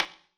surface_wood_table2.mp3